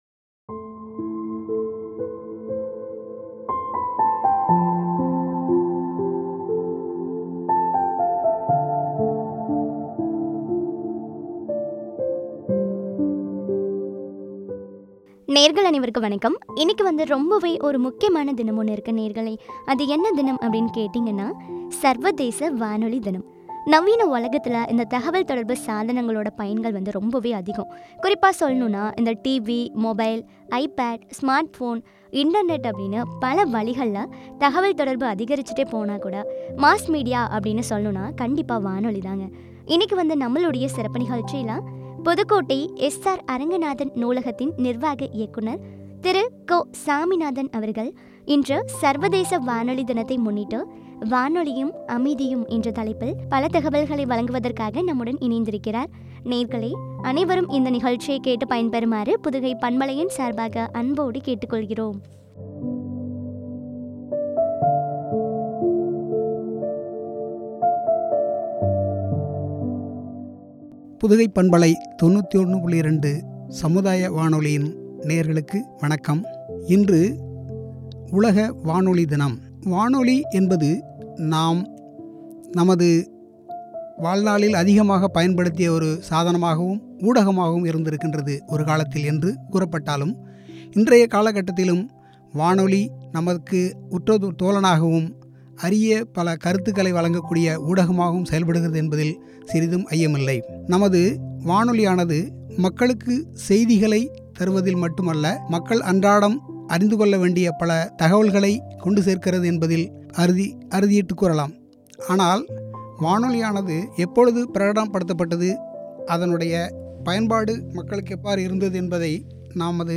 வானொலியும், அமைதியும் என்ற தலைப்பில் வழங்கிய உரையாடல்.